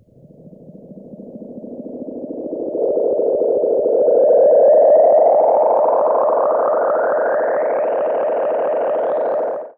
Filtered Feedback 03.wav